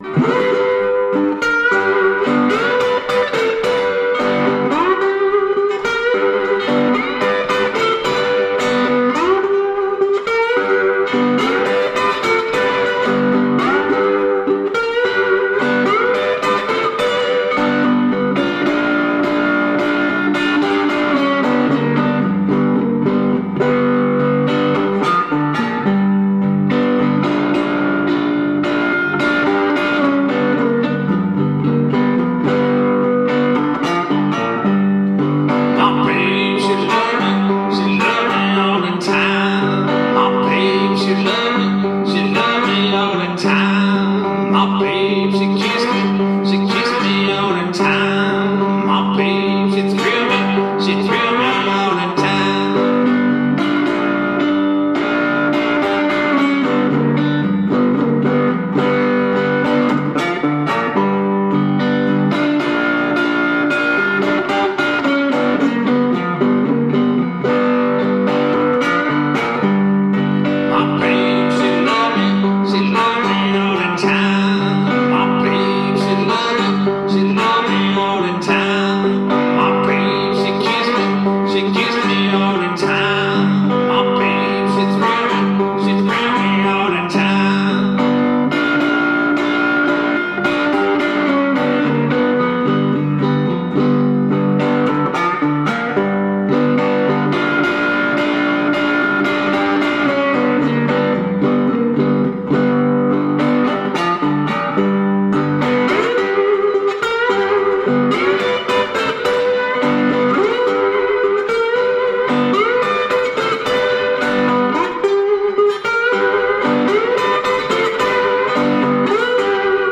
Authentic 1920's solo blues guitar .
Tagged as: Jazz, Blues, Vocal, Electric Guitar